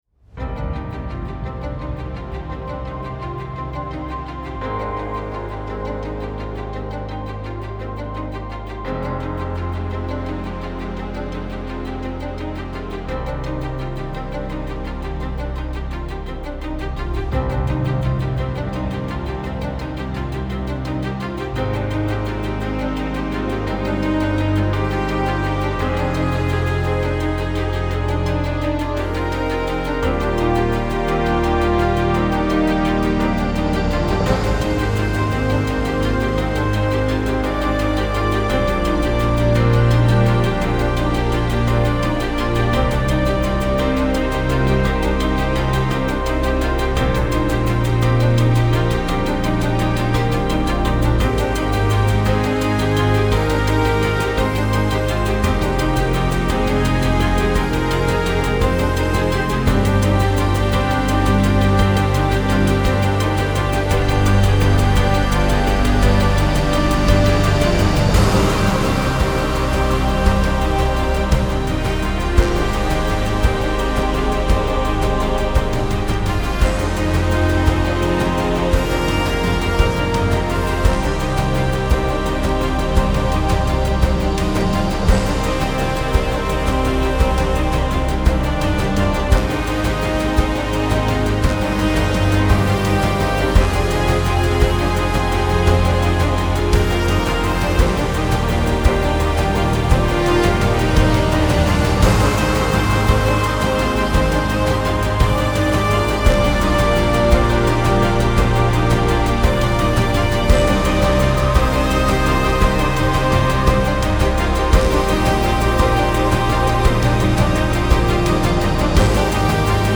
militärisch
Strings
abenteuerlich
emotional
dramatisch
ansteigend
groß
Rhythmus
Drums
episch
Mallets
dynamisch
Orchester
Plucked